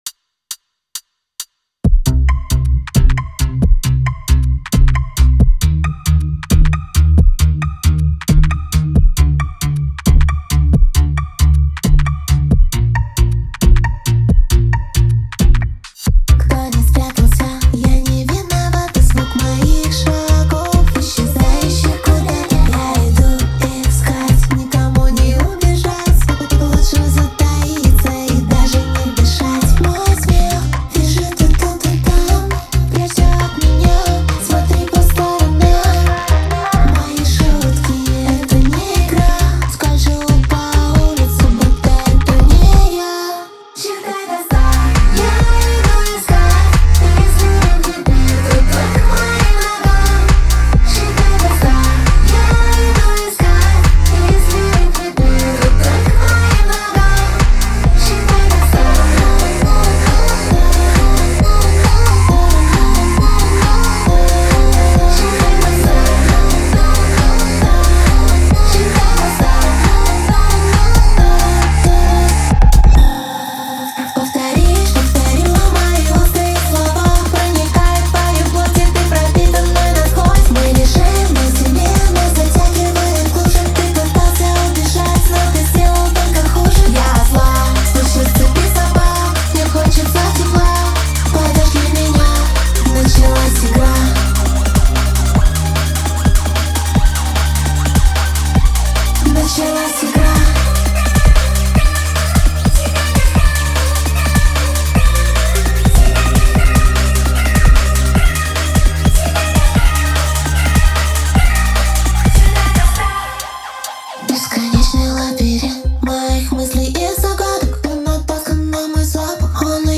это динамичная и загадочная композиция